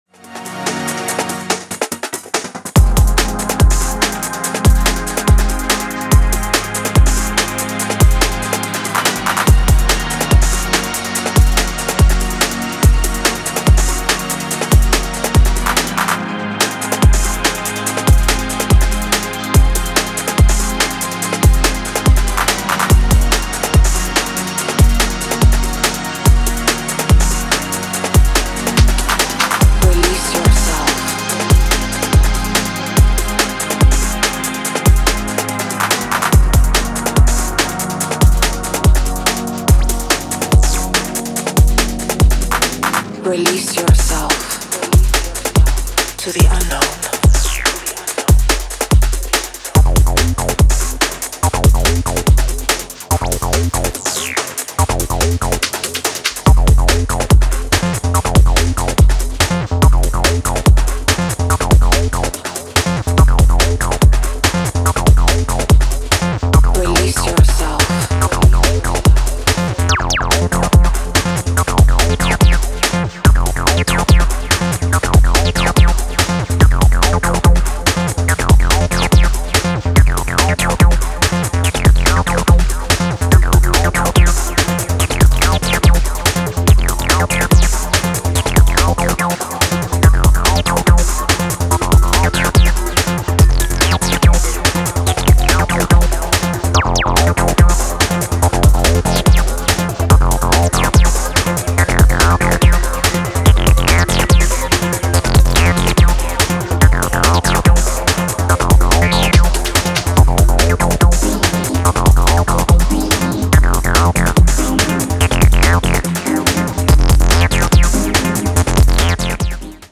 Electronix House Acid Bass Breaks